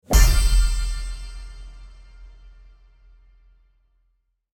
Deep Poof Impact With Big Sparkle Shine Sound Effect
A deep low hit with shiny, sparkling details and a large reverb creates a magical and powerful sound. This sound effect works perfectly for magical transformations, magic wand actions, and fantasy reveals.
Deep-poof-impact-with-big-sparkle-shine-sound-effect.mp3